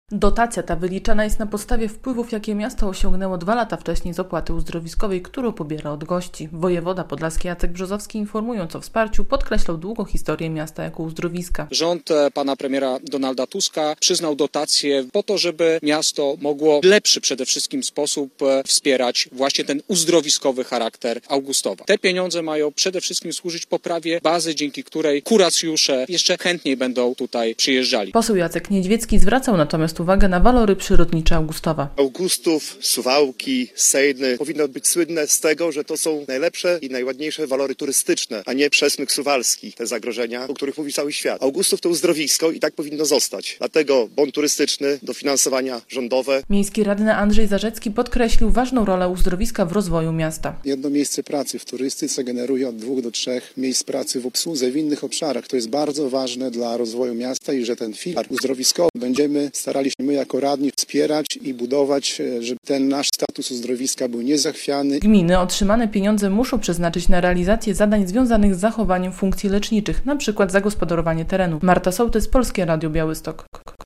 Blisko 500 tysięcy złotych otrzymały podlaskie uzdrowiska w ramach dorocznej dotacji dla miast uzdrowiskowych. 465 tysięcy złotych trafi do Augustowa, a 21 tysięcy złotych do Supraśla. O dofinansowaniu poinformował w poniedziałek (7.07) wojewoda podlaski wraz z podlaskimi posłami podczas konferencji prasowej w Augustowie.
relacja